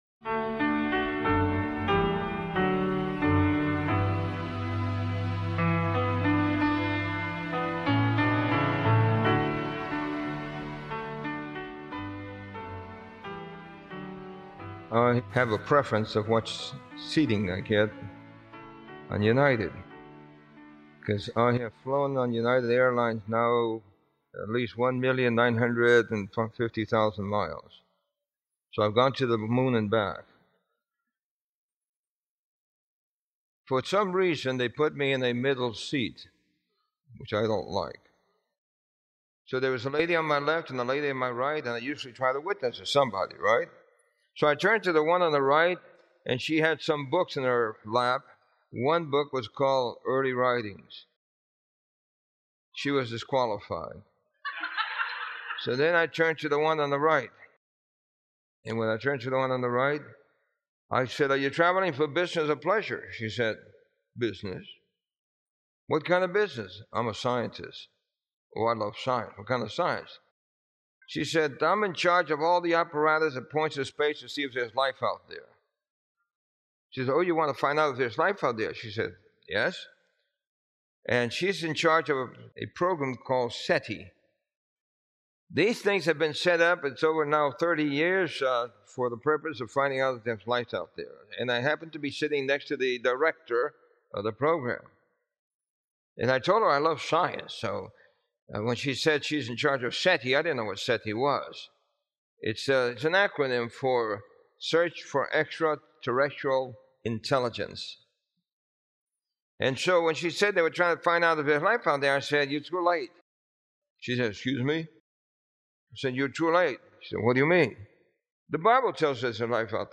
This sermon explores how Scripture reveals scientific and cosmic truths long before modern discovery, from medicine and human strength to the movement of the stars. By connecting biblical prophecy with astronomy and real-world science, it presents Christ’s return as a literal, awe-inspiring rescue mission—inviting listeners to respond with faith, commitment, and hope.